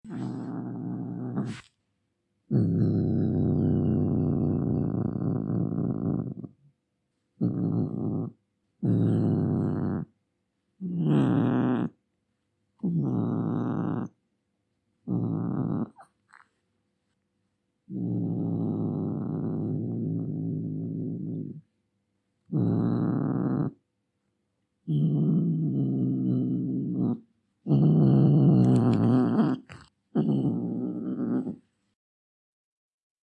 Angry Cat Sound Effcet Sound Button: Unblocked Meme Soundboard